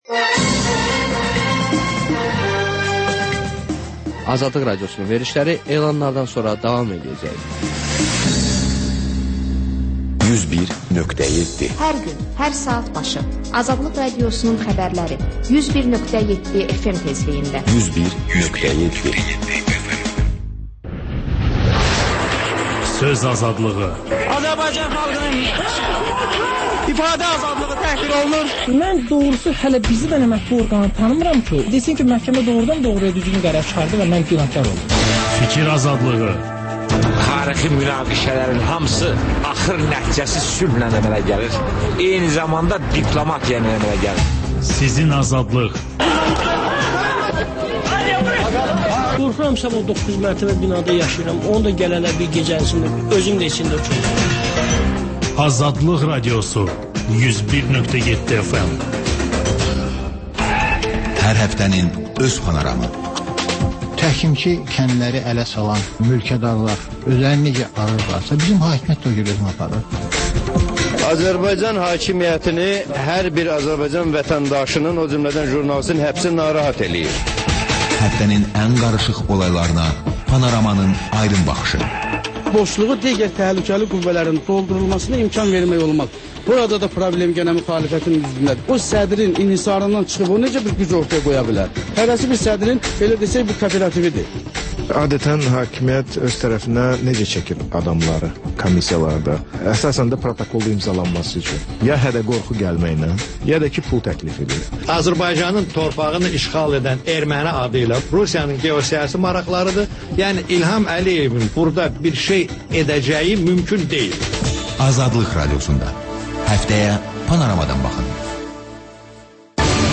Xəbərlər, HƏMYERLİ: Xaricdə yaşayan azərbaycanlılar haqda veriliş, sonda MÜXBİR SAATI: Müxbirlərimizin həftə ərzində hazırladıqları ən yaxşı reportajlardan ibarət paket